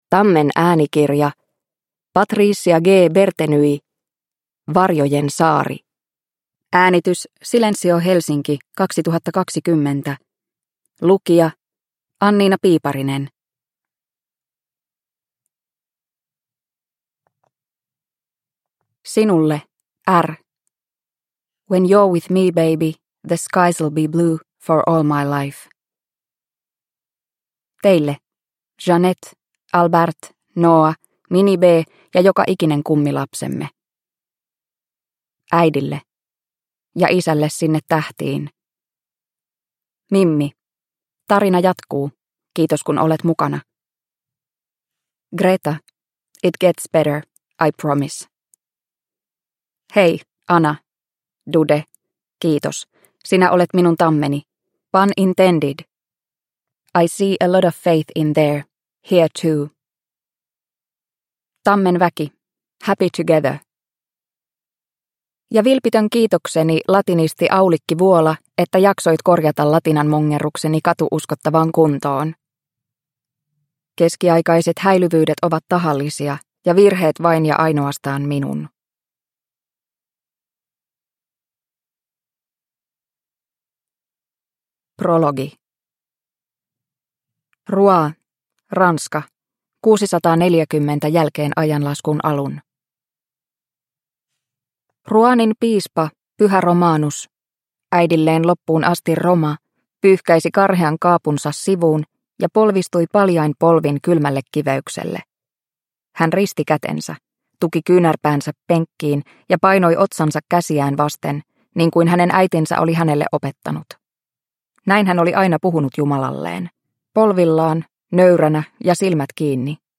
Varjojen saari – Ljudbok – Laddas ner